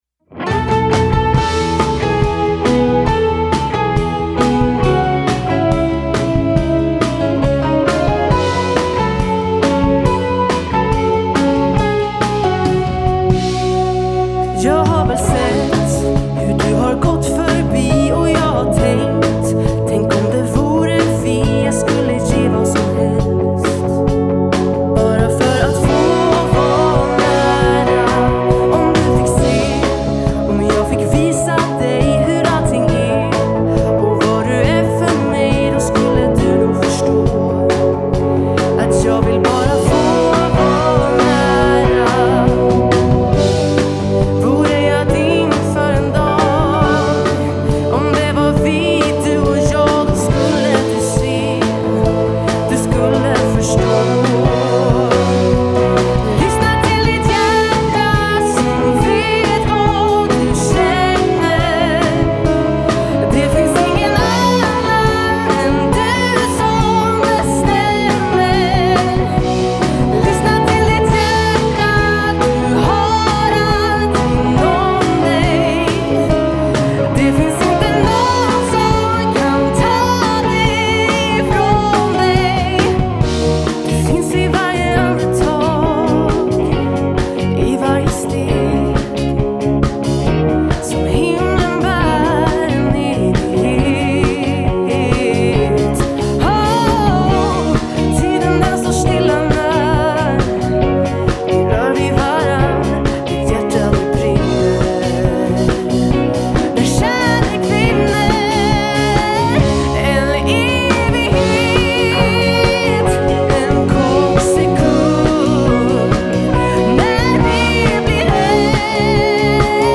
Sång
Piano/keys
Gitarr
Trummor